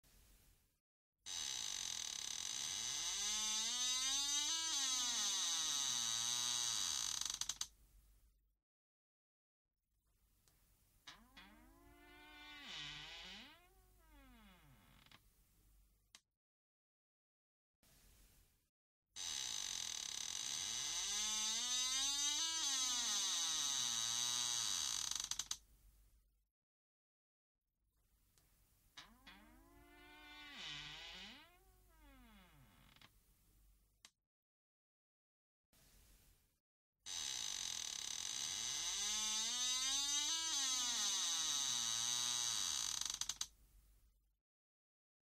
door creak